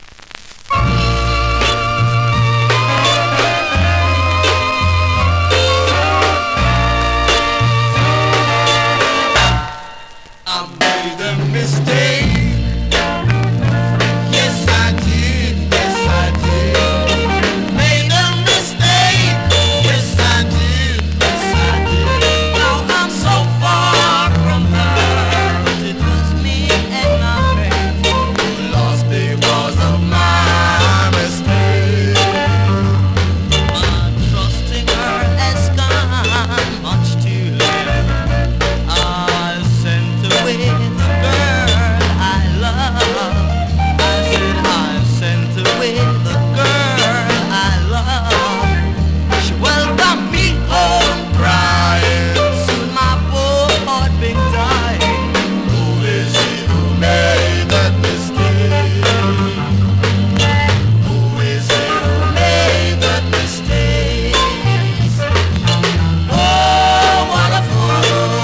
SKA
スリキズ、ノイズかなり少なめの